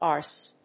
/ɑrs(米国英語), ɑ:rs(英国英語)/
フリガナアース